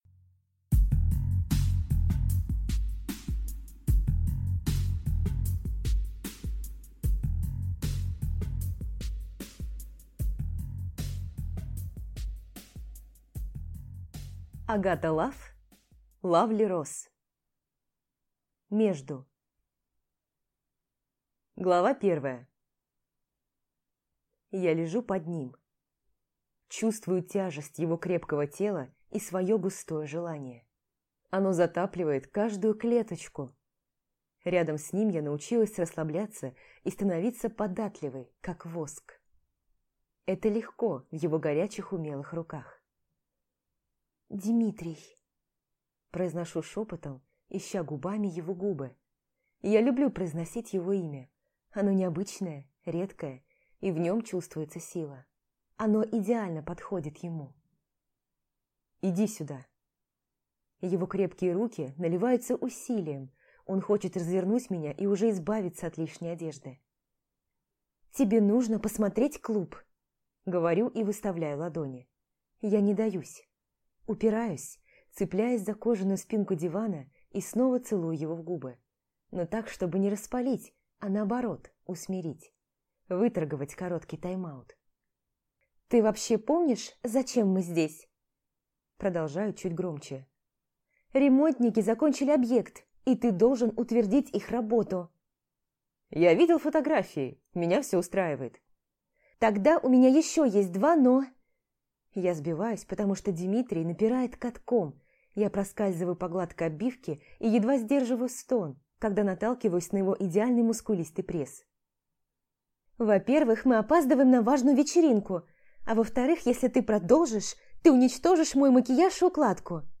Аудиокнига Между | Библиотека аудиокниг